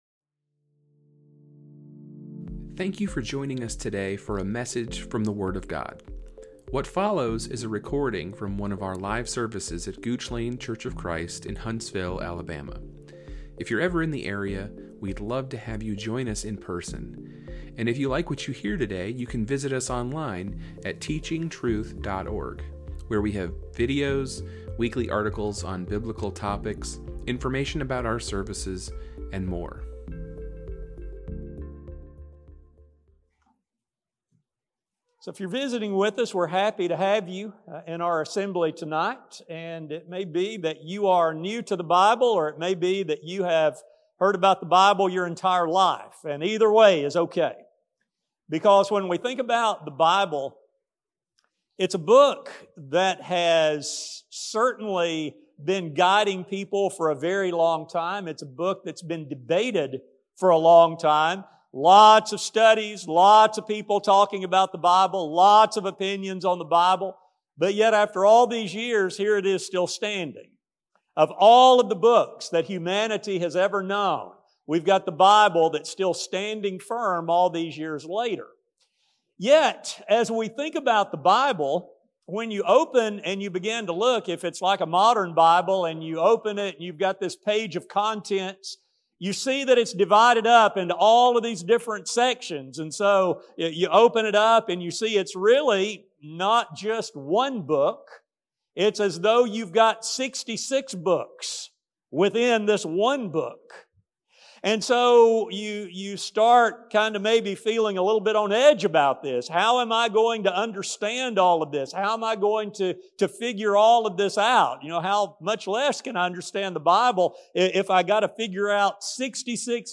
This is a recording from our Community Bible Study on March 5, 2023.